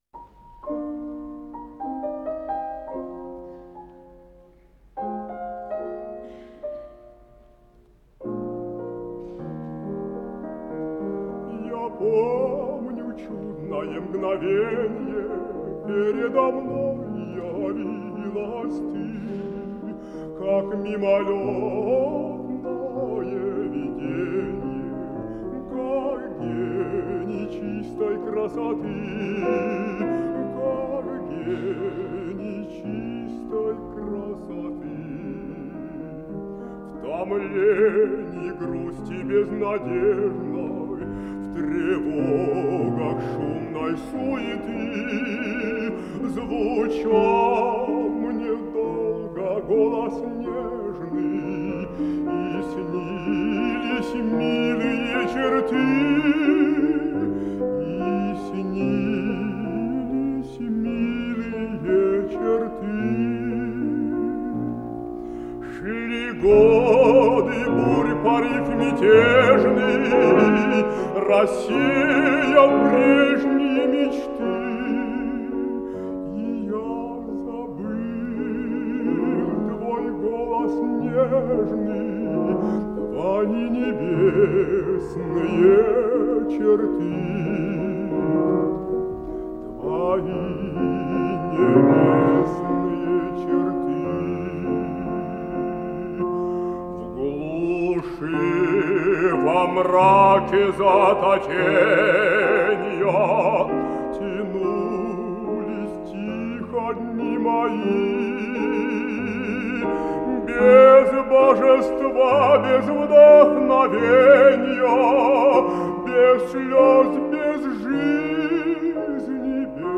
Жанр: Вокал
ф-но
Большой зал Консерватории
баритон